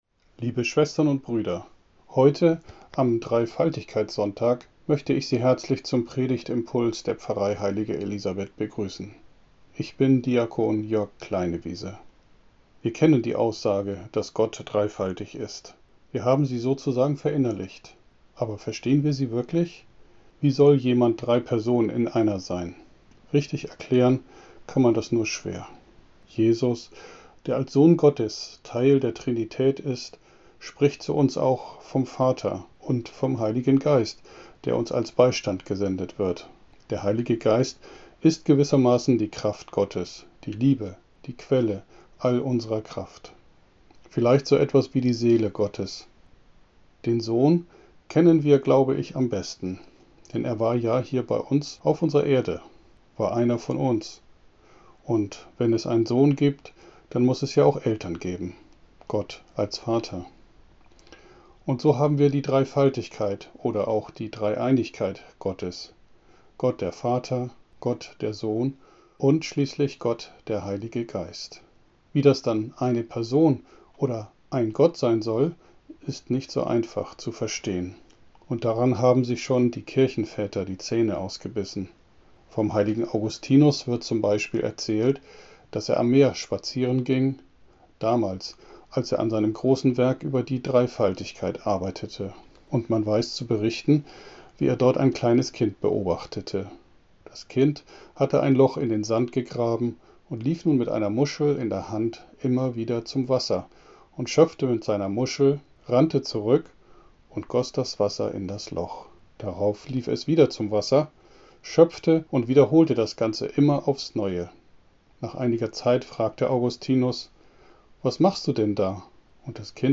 Dreifaltigkeit – Sonntagsimpuls 30.05.2021